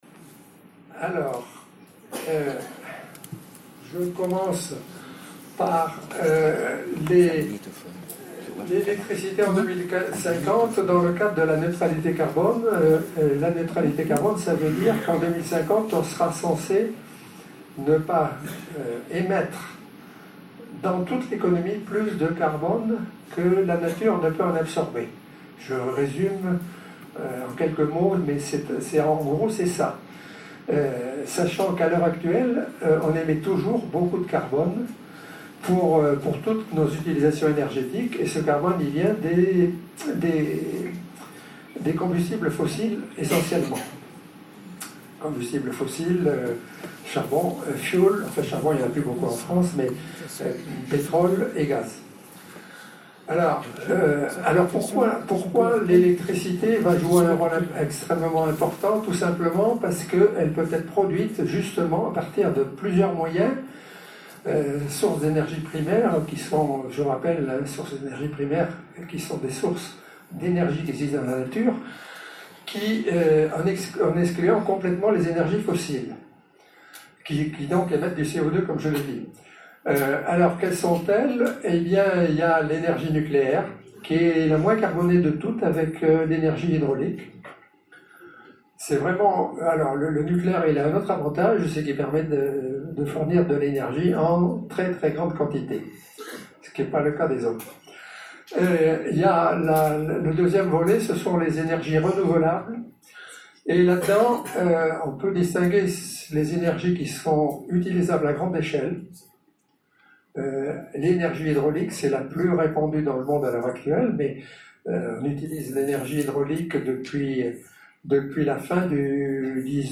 Enregistrement de la conférence + questions / réponses